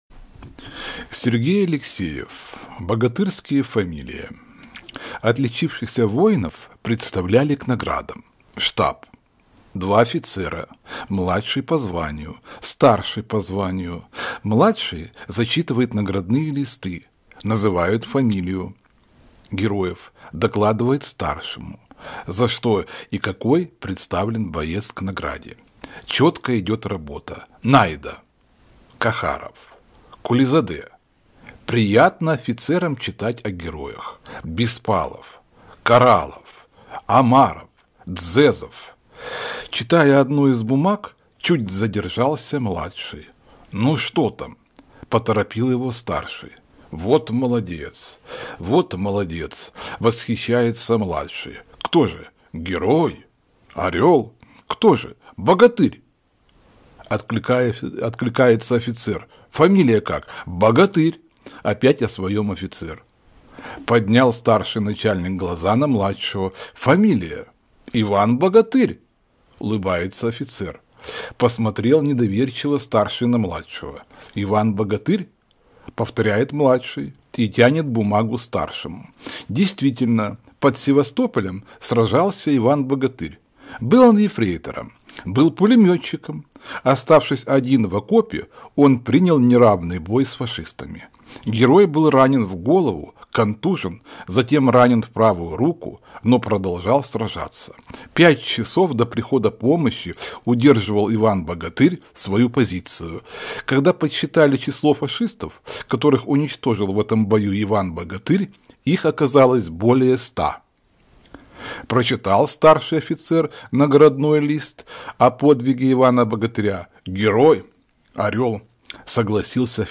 Аудиорассказ «Богатырские фамилии»